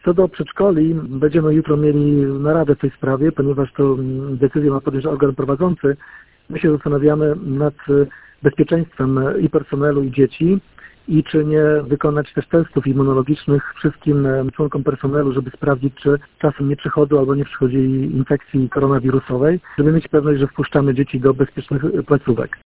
Jak mówi Wojciech Karol Iwaszkiewicz, burmistrz Giżycka, 30 kwietnia odbędzie się specjalna narada.